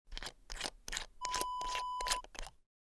Small Glass Bottle Open Wav Sound Effect #2
Description: The sound of opening a cap of a small glass bottle
Properties: 48.000 kHz 16-bit Stereo
A beep sound is embedded in the audio preview file but it is not present in the high resolution downloadable wav file.
Keywords: small, glass, bottle, metal, cap, lid, open, opening, twist, twisting, pill, tablet, medicine, spice, jar, container
small-glass-bottle-open-preview-2.mp3